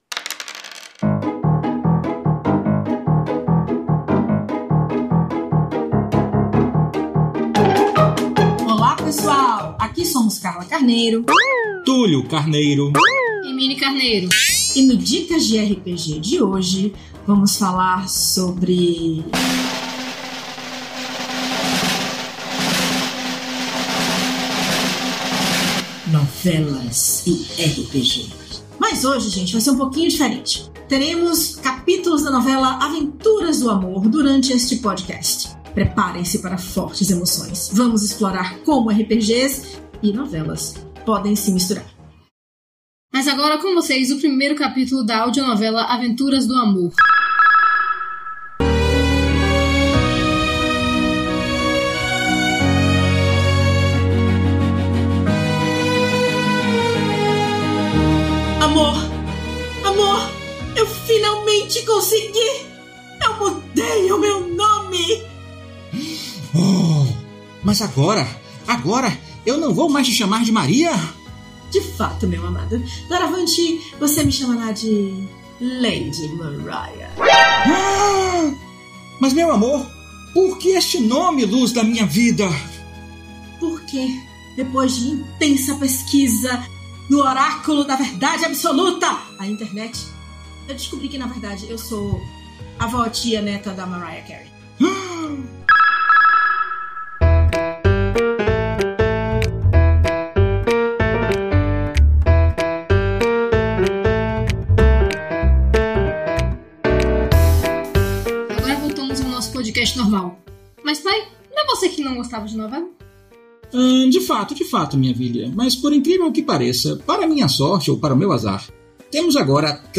Dicas de RPG Podcasts
Músicas: Music by from Pixabay